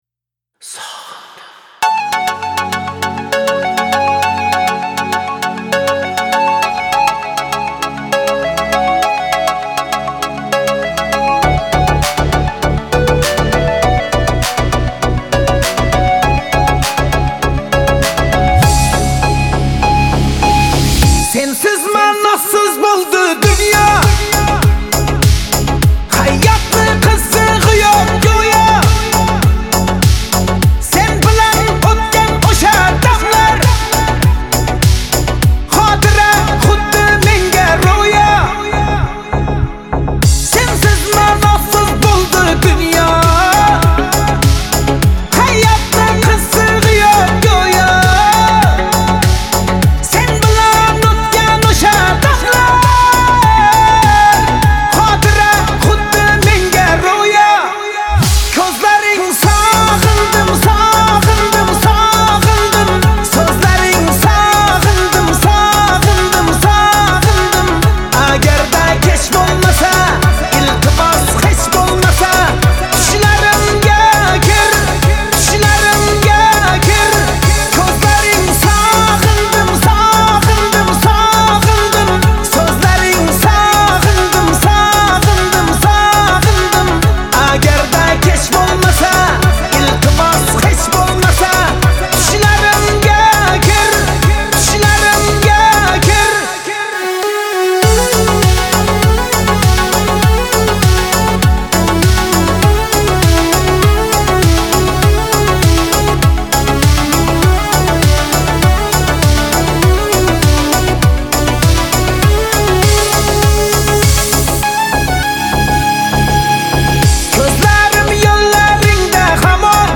Хорезмские песни